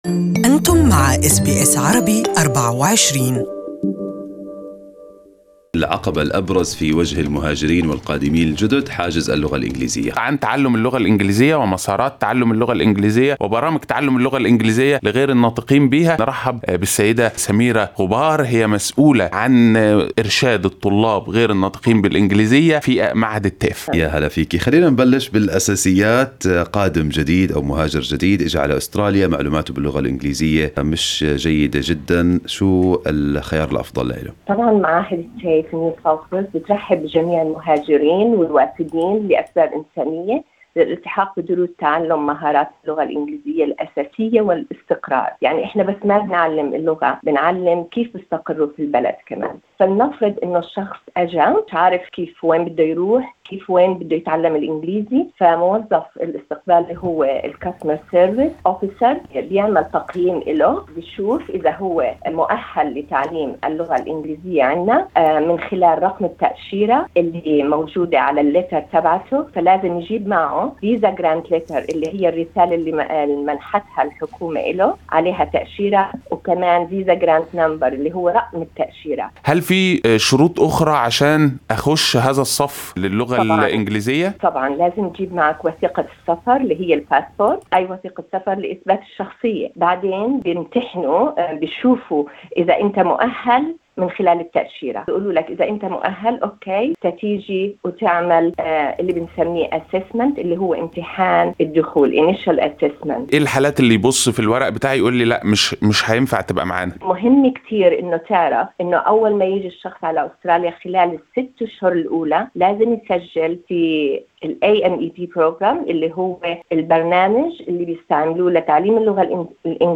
مقابلة مع مسؤولة في معهد TAFE في نيو ساوث ويلز لشرح الخيارات المتاحة أمام القادمين الجدد الراغبين بتعلم اللغة الانجليزية.